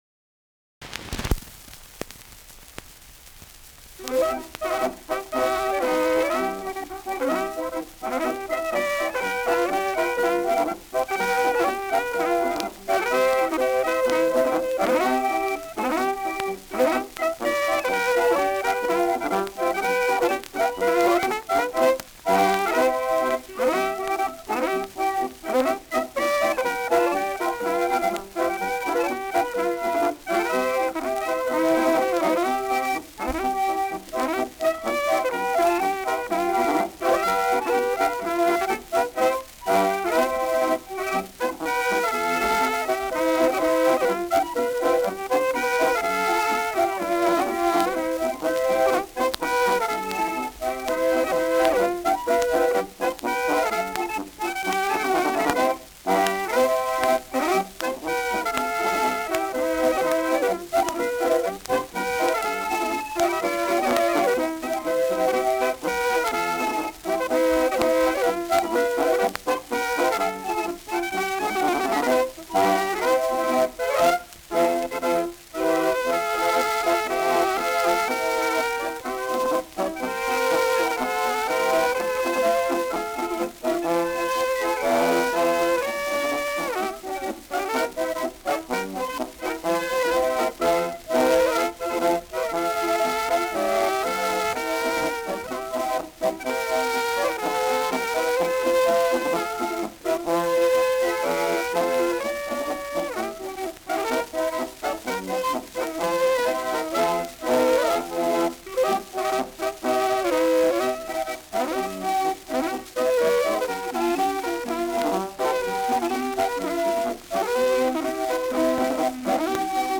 Schellackplatte
leichtes Rauschen